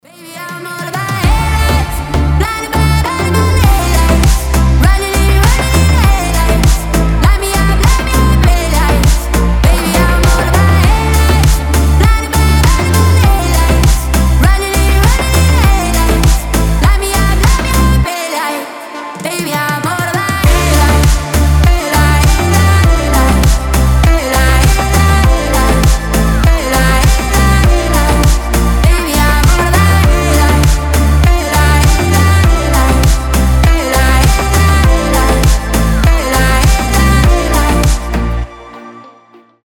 Midtempo